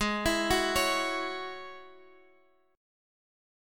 Ab7sus4#5 chord